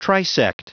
Prononciation du mot trisect en anglais (fichier audio)
Prononciation du mot : trisect